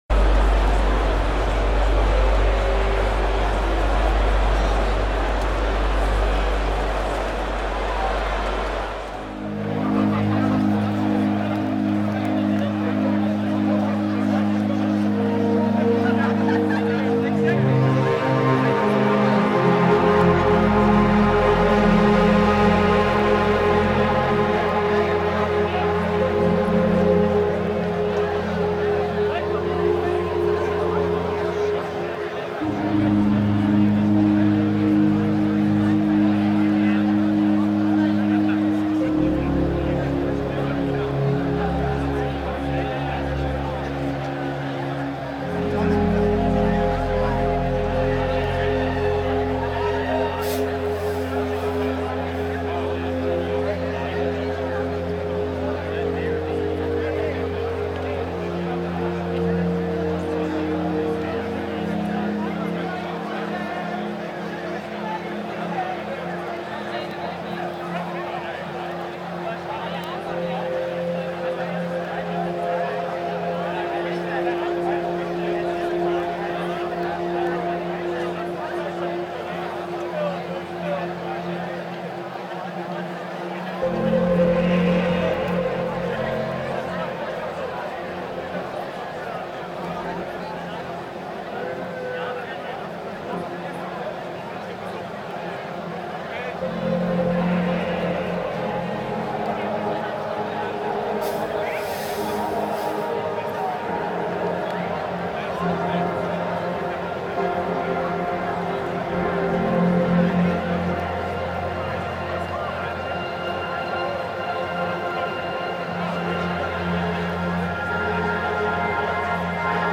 liveset